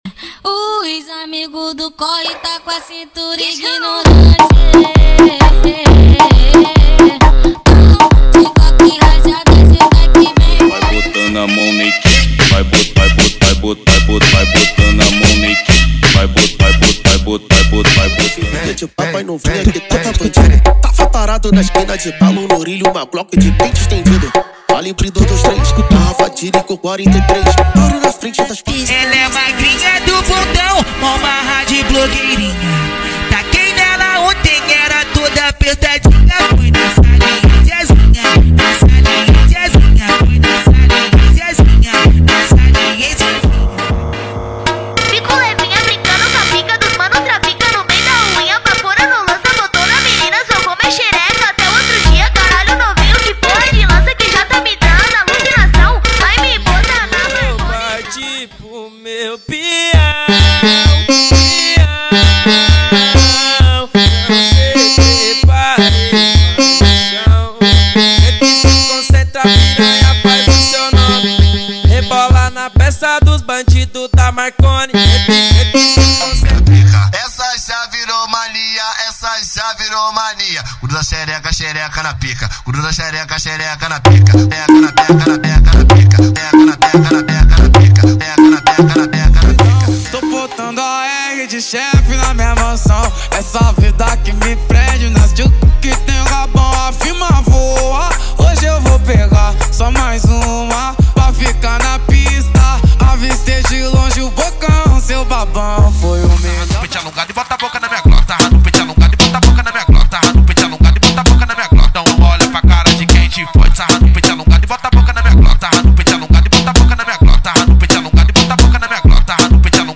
• Funk Proibidão + mandelão = 150 Músicas